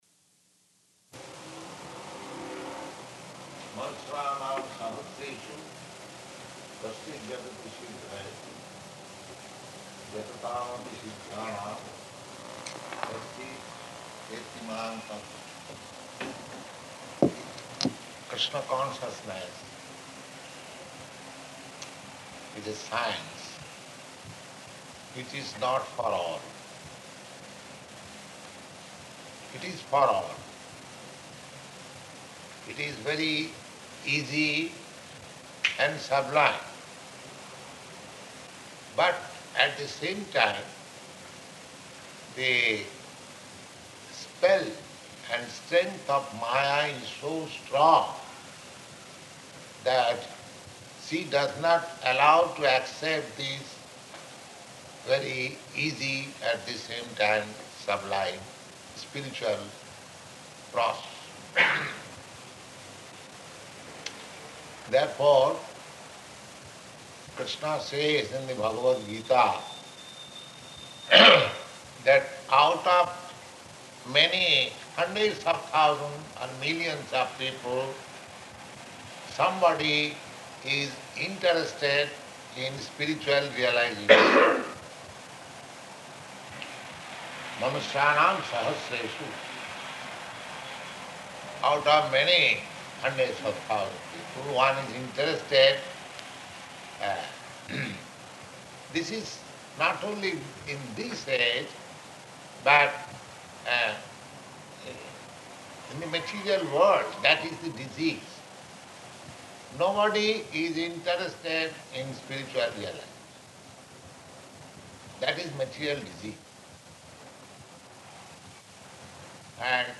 Bhagavad-gītā 7.3 --:-- --:-- Type: Bhagavad-gita Dated: June 3rd 1968 Location: Montreal Audio file: 680603BG.MON.mp3 Prabhupāda: That Mr What is his name?